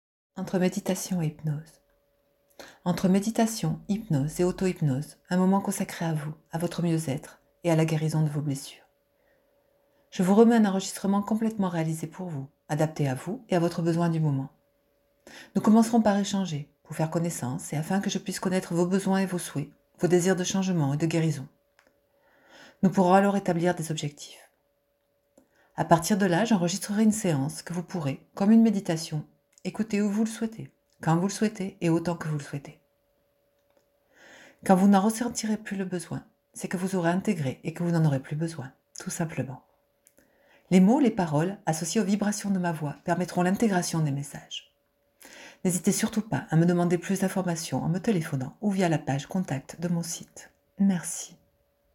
Entre méditation et hypnose
Les mots, les paroles, associés aux vibrations de ma voix permettront l'intégration des messages.
mditation-hypnose.mp3